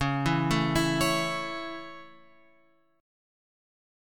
C#m#5 chord {9 7 7 9 10 x} chord